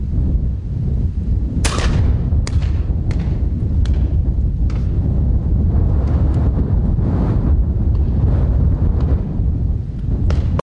野鸡射击包1 " 两次近距离射击
描述：在野鸡射击期间从并排射手拍摄的声音。在SONY FS7上使用RODE NTG1霰弹枪录制，音频直接从.mxf文件中提取并渲染为48kHz WAV文件。
Tag: 野鸡 射击 并排侧 过度和下 季节 射击 猎枪 射击 一声枪响 开枪